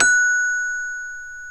CELESTE 2 0C.wav